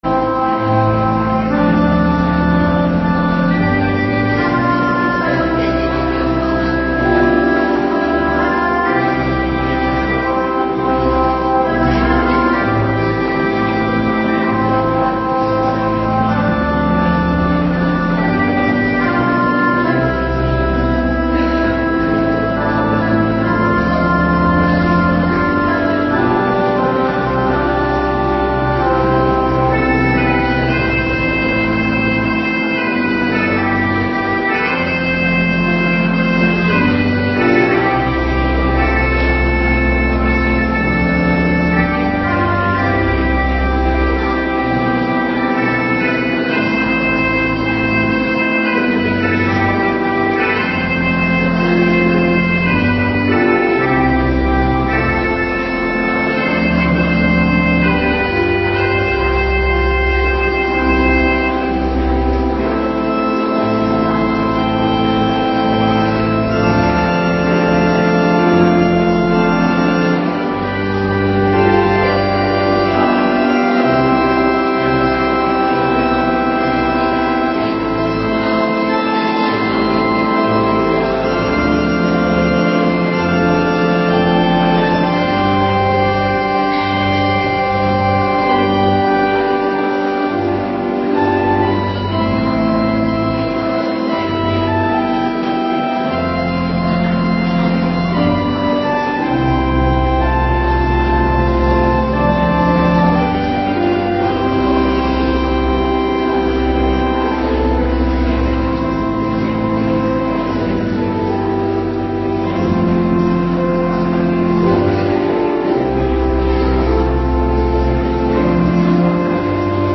Middagdienst 11 maart 2026
Hervormde gemeente Wilnis